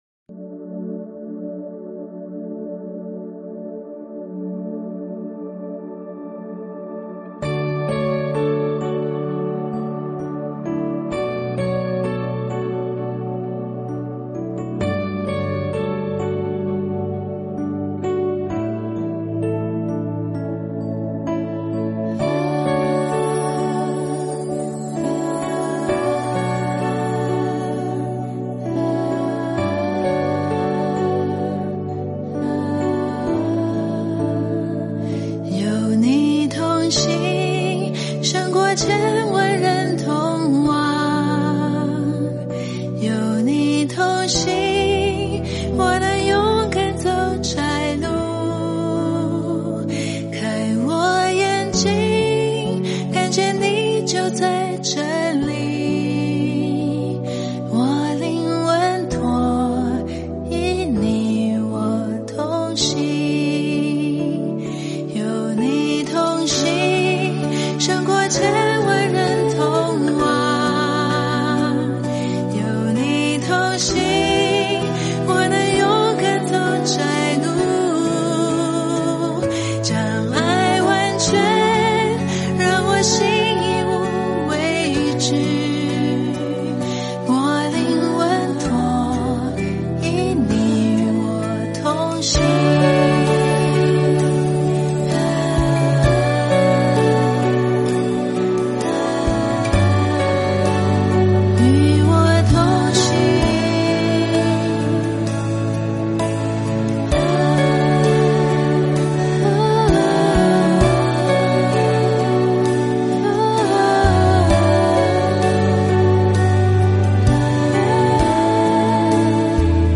赞美诗 | 有你同行